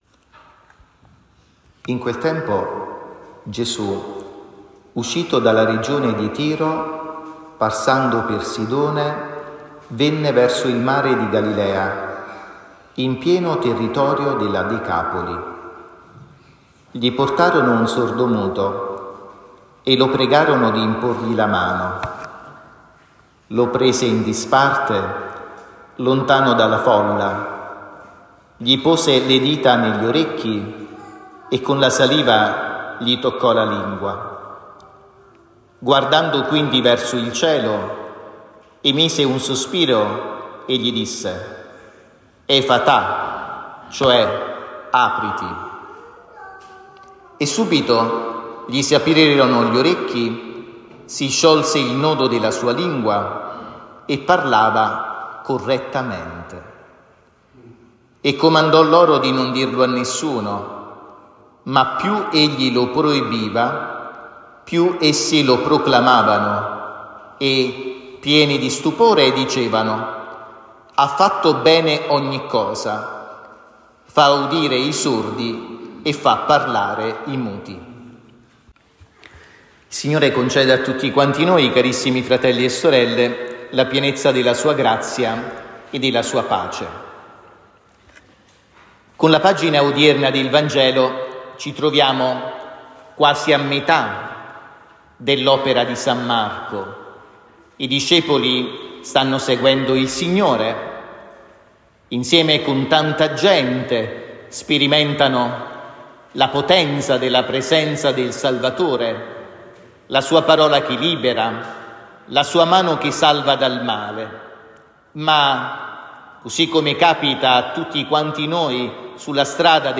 omelia-8-sett-2024.mp3